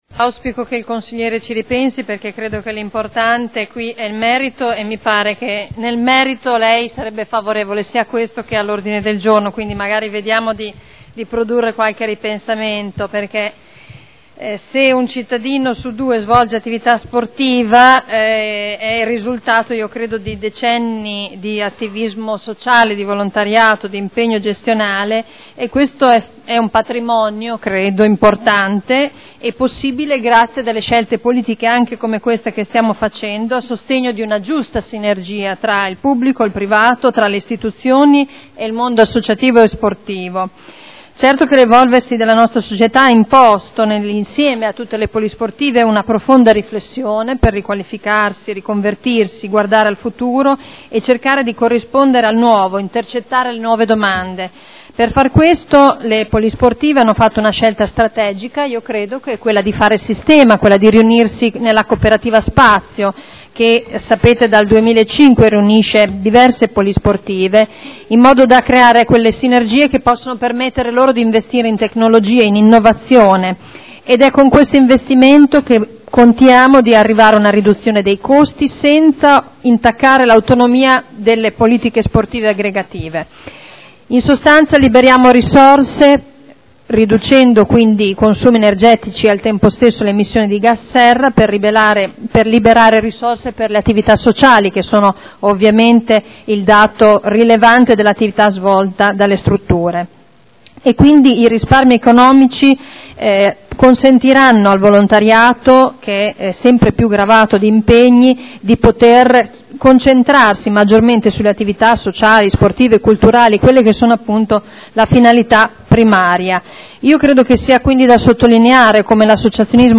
Seduta del 21/03/2011. Dibattito su proposta di deliberazione: Diritto di superficie a favore della Cooperativa Spazio Unimmobiliare per gli impianti ubicati presso la Polisportiva Saliceta San Giuliano e presso la Polisportiva Gino Nasi – Autorizzazione a iscrivere ipoteca”